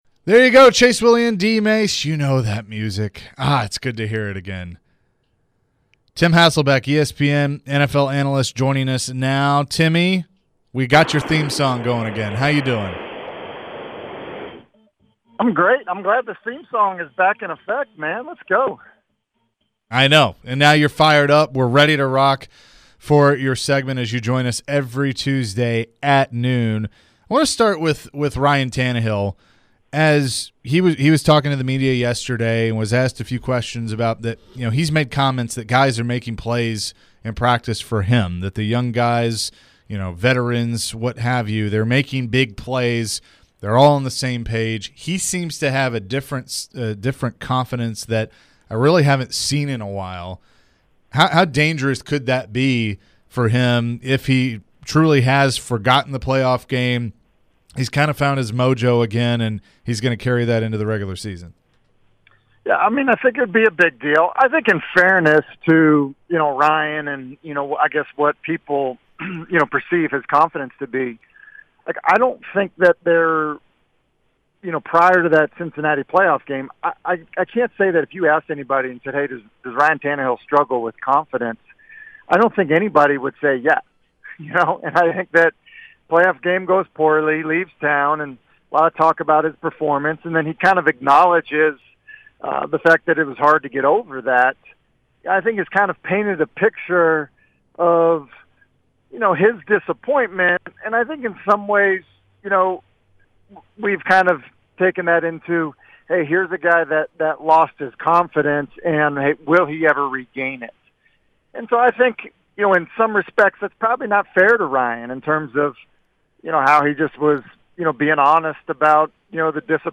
Tim Hasselbeck Full Interview (08-09-22)